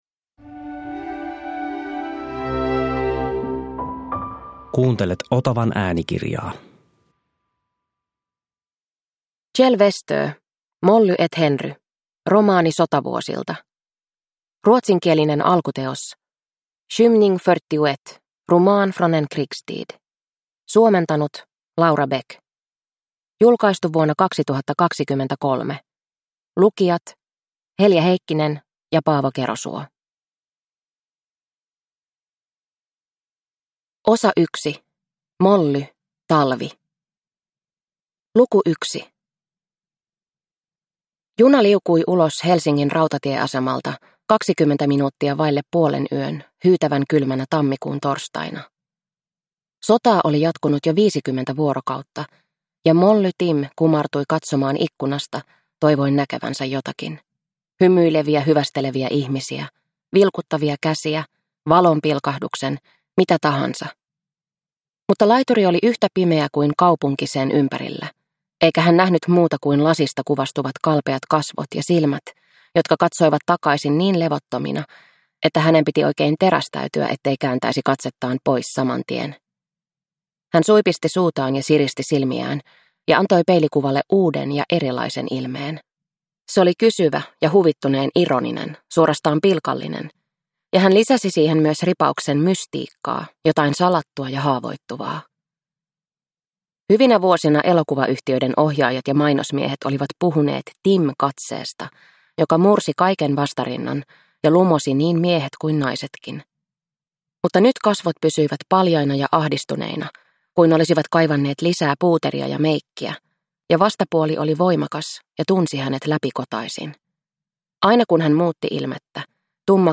Molly & Henry – Ljudbok – Laddas ner